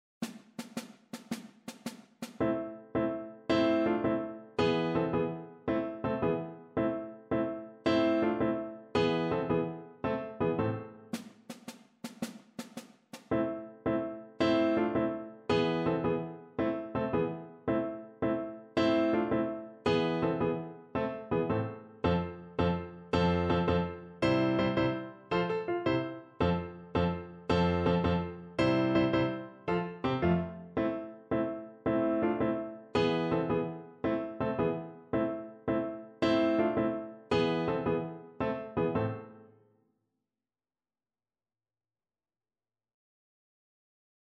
Schumann: Śmiały jeździec (na skrzypce i fortepian)
Symulacja akompaniamentu